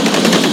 TRAINTRX.WAV